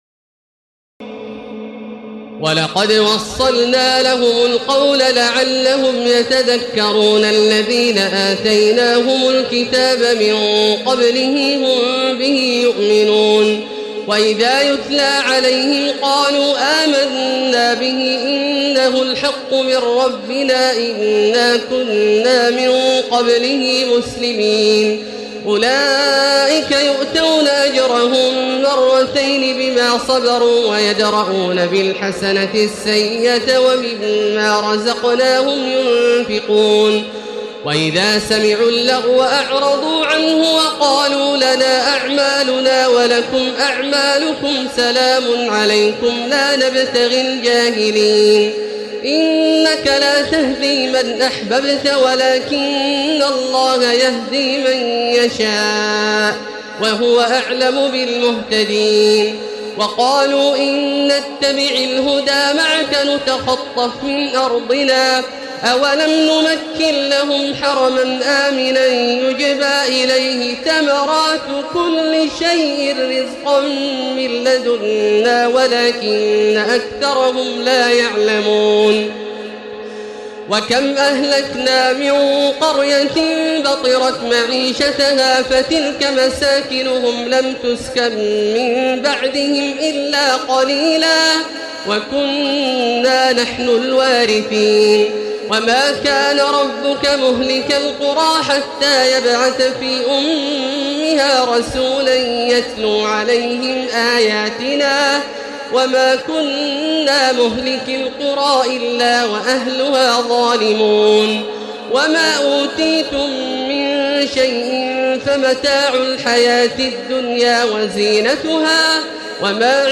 تراويح الليلة التاسعة عشر رمضان 1436هـ من سورتي القصص (51-88) والعنكبوت (1-45) Taraweeh 19 st night Ramadan 1436H from Surah Al-Qasas and Al-Ankaboot > تراويح الحرم المكي عام 1436 🕋 > التراويح - تلاوات الحرمين